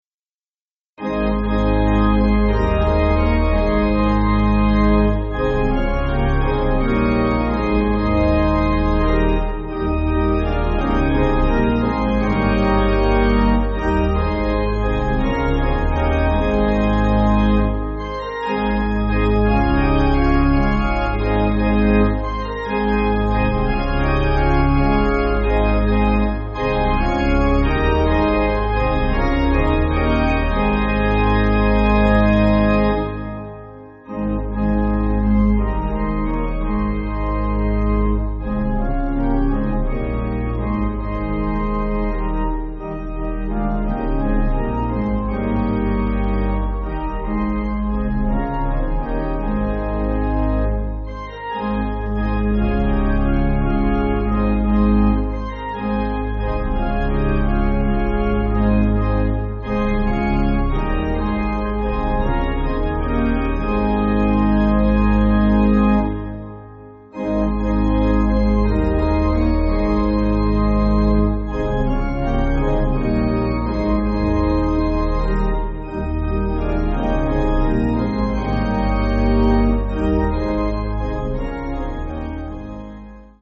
Organ